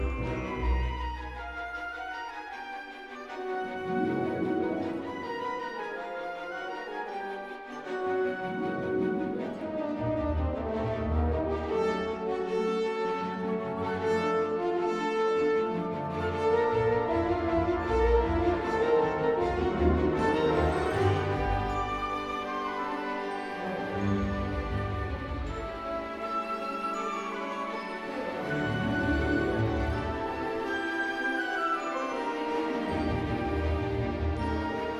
Жанр: Музыка из фильмов / Саундтреки / Африканская музыка